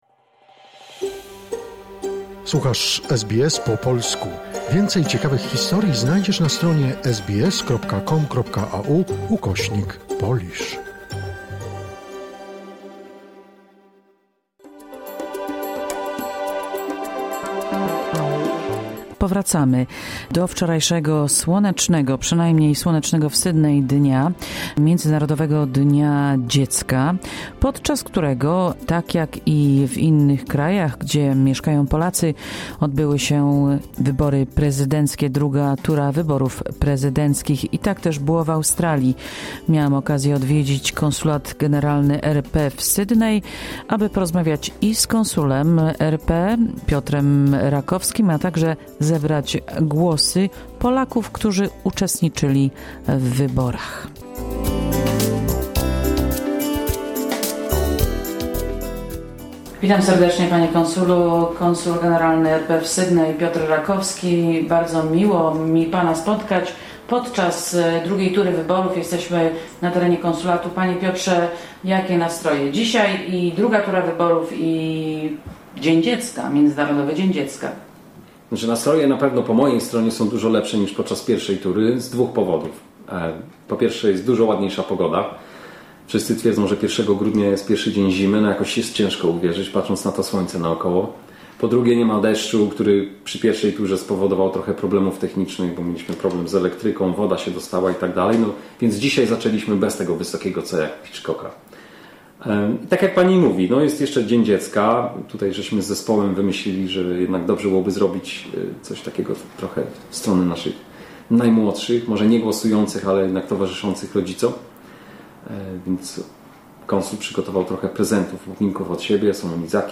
Jak minął dzień II tury polskich wyborów prezydenckich w Australii? W cały, kraju zagłosowało ponad 4000 Polaków, mówi Konsul RP w Sydney Piotr Rakowski.